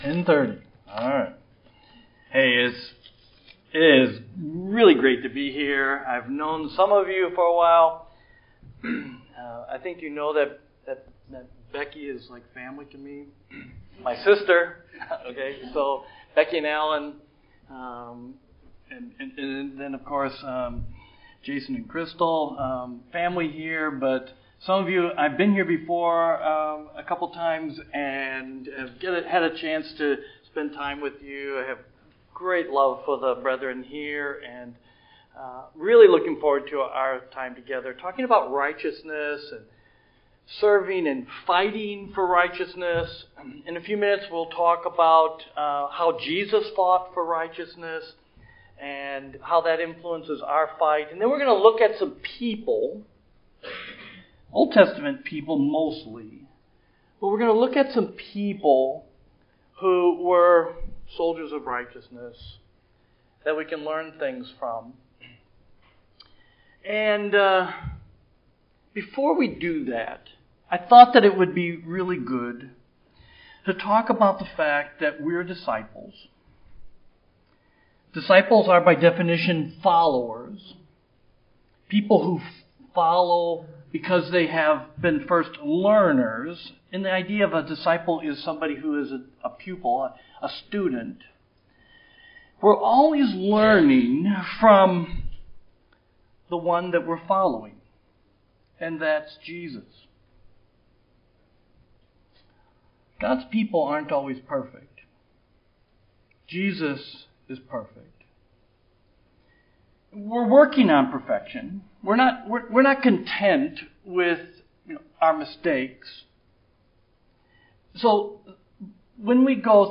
1110BibleClass.mp3